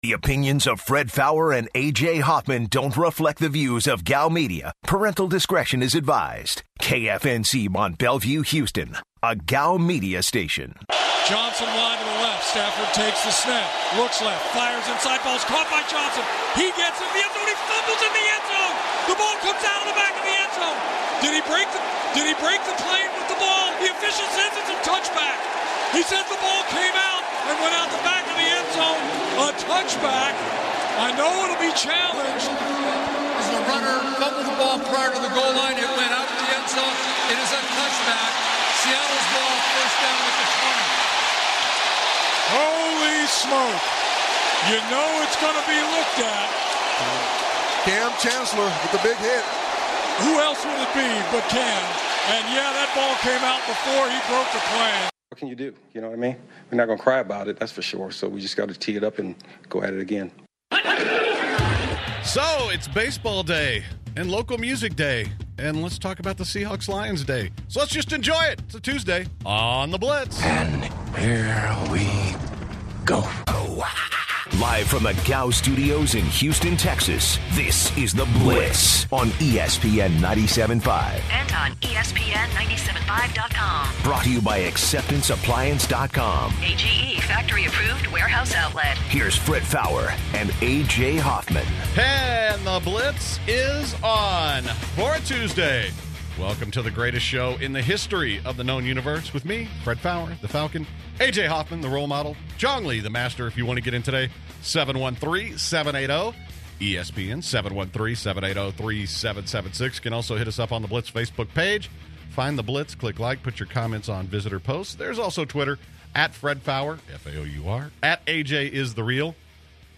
Also, the guys take calls from fellow blitzers!